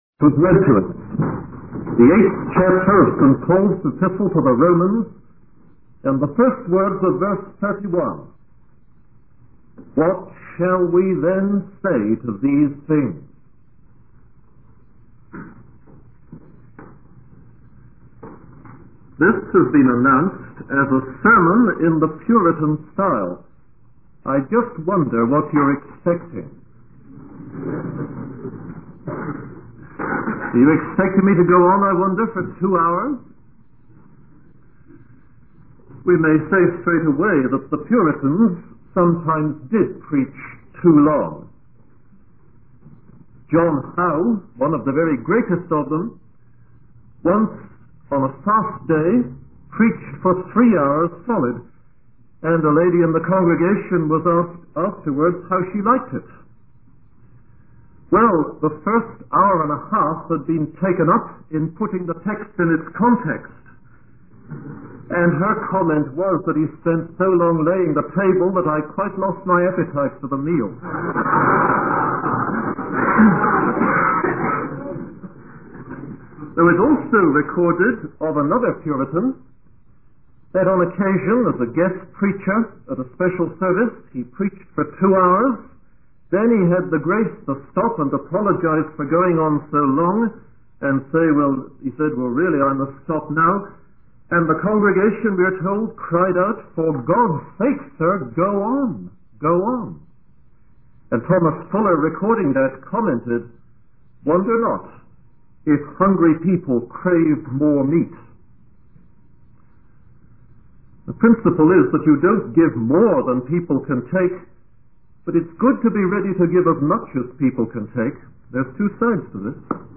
In this sermon, the speaker focuses on four key truths that every believer should react to. The first truth is that if these things are true, no opposition can triumph over us.